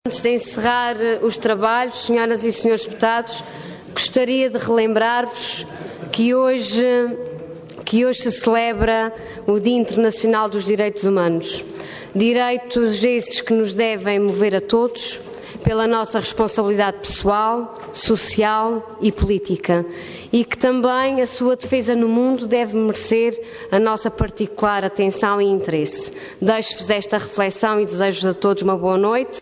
Detalhe de vídeo 10 de dezembro de 2014 Download áudio Download vídeo X Legislatura Alusão ao Dia Internacional dos Direitos Humanos Intervenção Orador Ana Luísa Luís Cargo Presidente da Assembleia Regional Entidade ALRAA